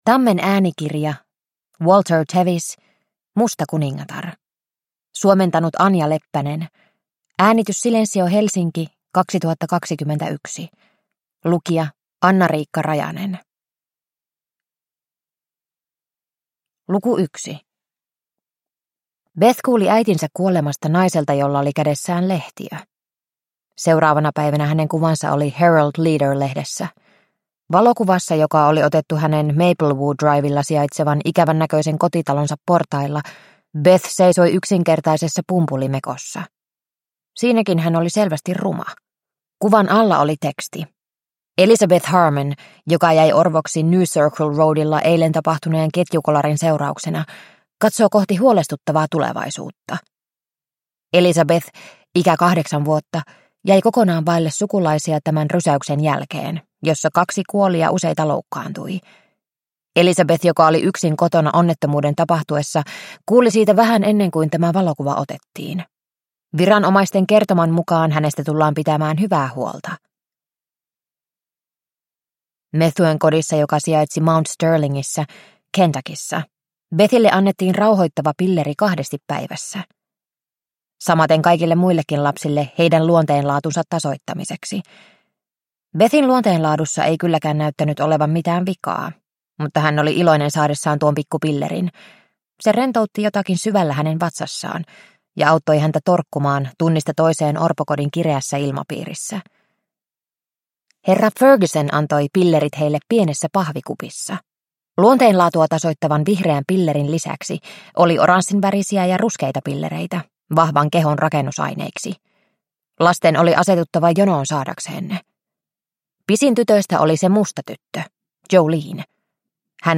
Musta kuningatar – Ljudbok – Laddas ner